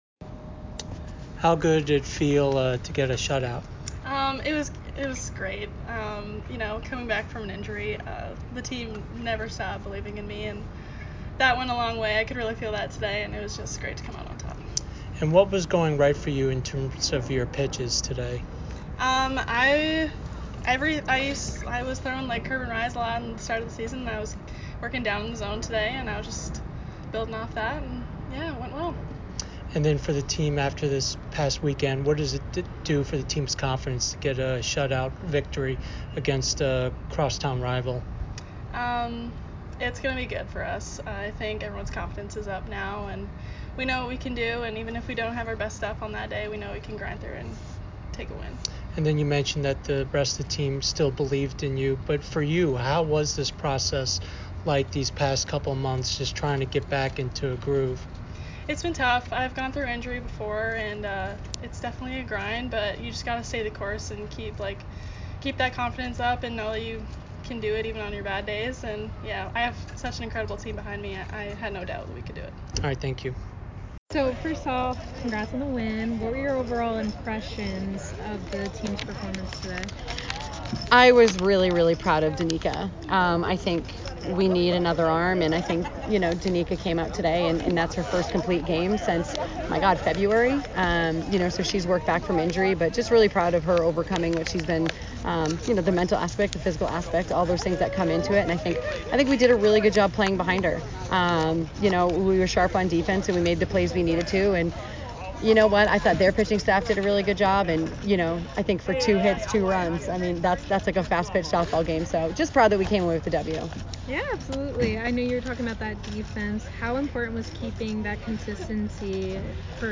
BU-Harv-Postgame.mp3